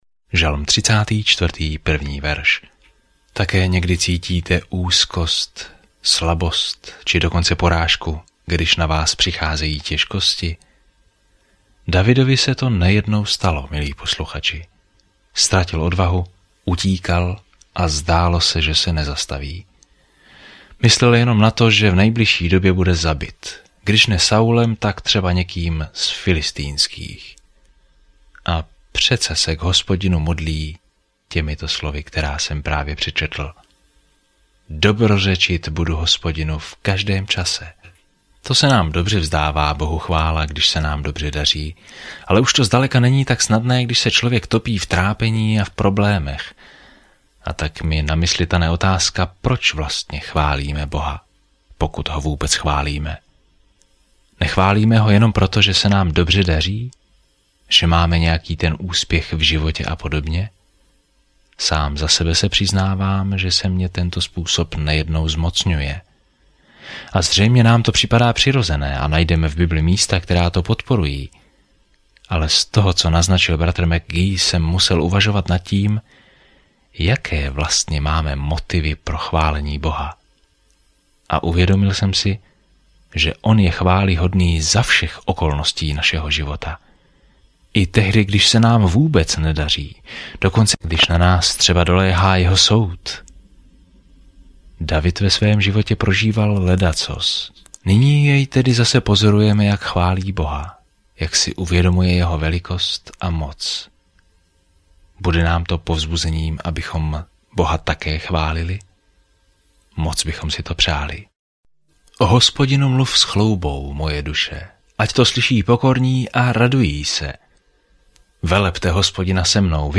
Písmo Žalmy 34 Žalmy 35 Žalmy 36 Den 25 Začít tento plán Den 27 O tomto plánu Žalmy nám dávají myšlenky a pocity z řady zkušeností s Bohem; pravděpodobně každý z nich původně zhudebnil. Denně procházejte žalmy, poslouchejte audiostudii a čtěte vybrané verše z Božího slova.